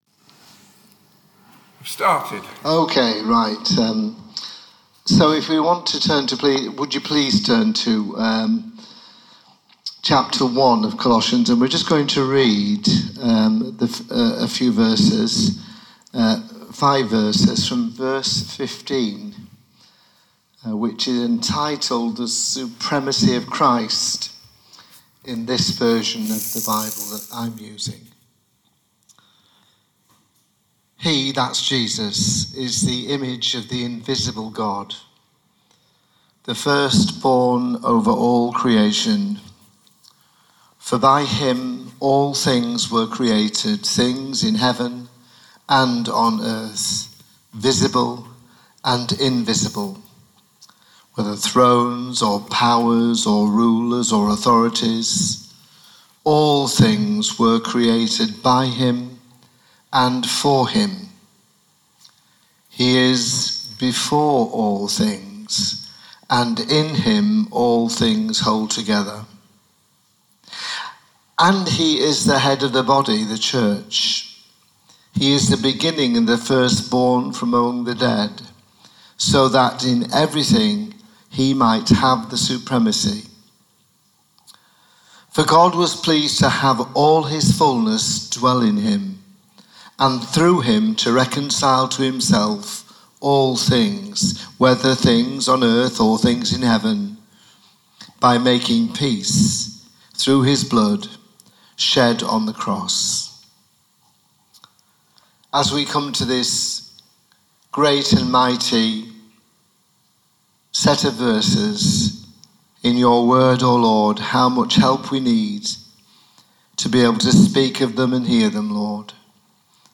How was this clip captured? This week the online service is a �replay� of the in-person service on December 14 th .